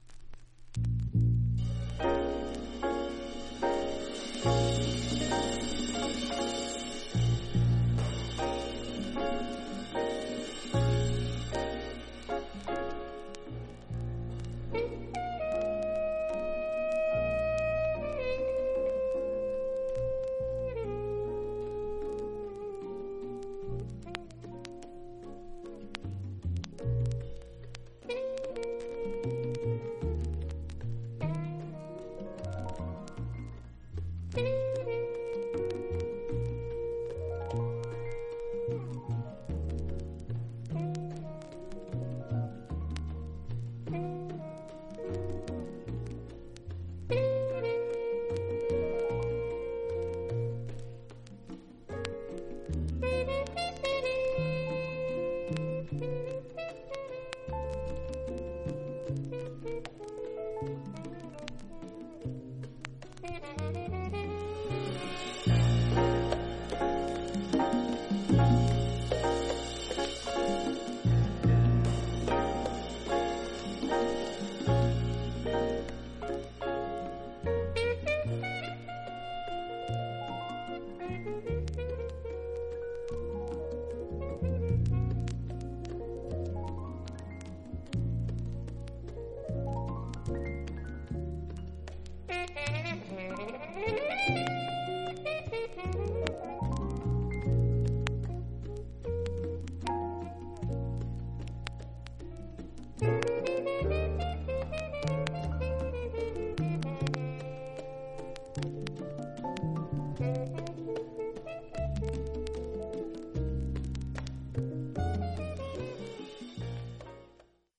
71年作のスピリチュアルジャズ。
※盤面薄いスリキズが全体的にあります。
実際のレコードからのサンプル↓ 試聴はこちら： サンプル≪mp3≫